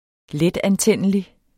Udtale [ ˈlεdanˌtεnˀəli ]